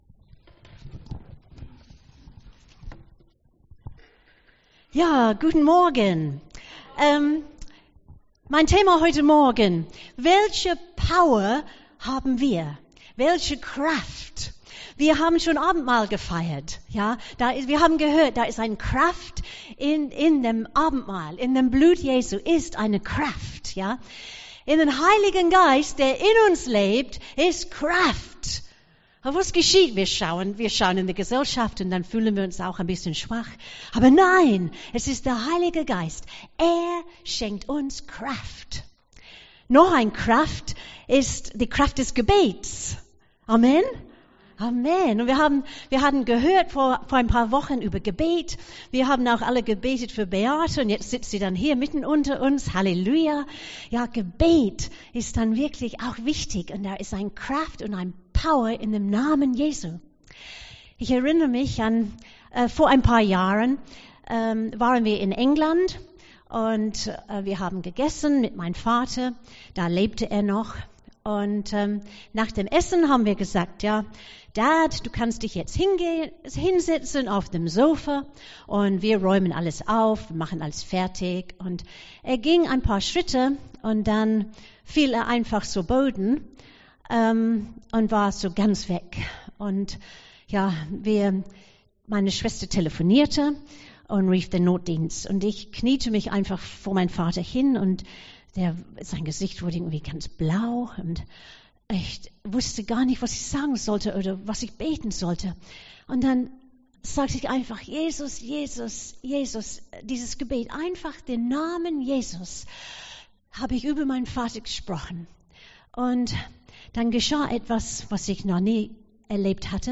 Predigt 01.03.2020